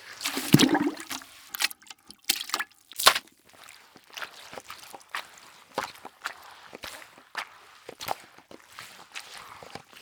mop.wav